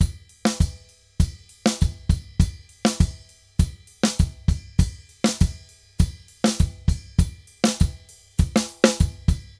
Drums_Samba 100_4.wav